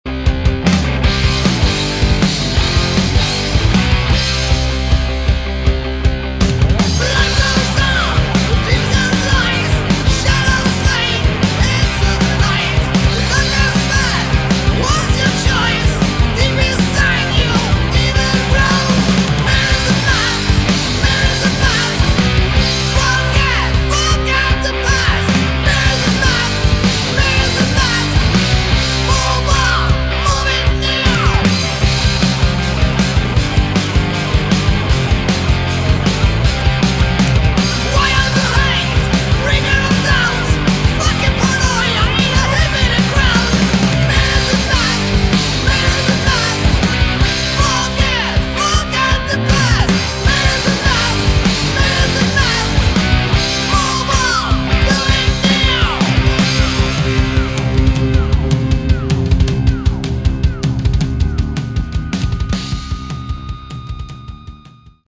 Category: Hard Rock
Vocals
Lead Guitars
Rhythm Guitar
Bass
Drums